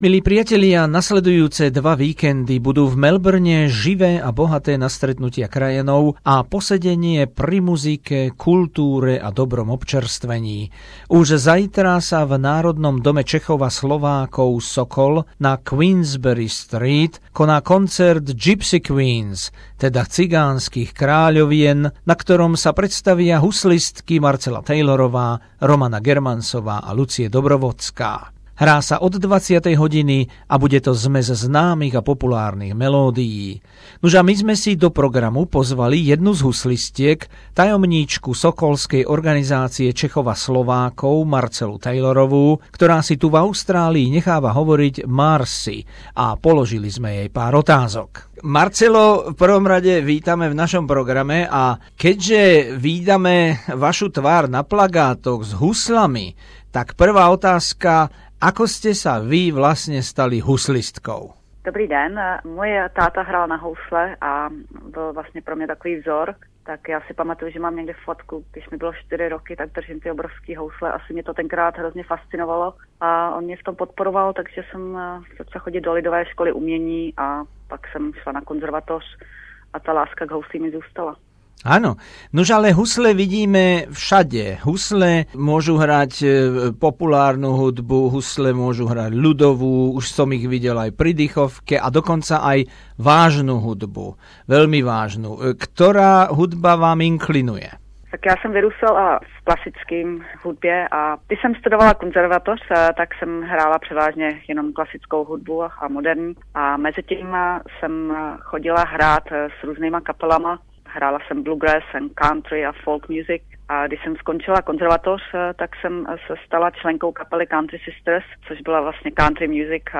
Rozhovor s českou huslistkou žijúcou v Melbourne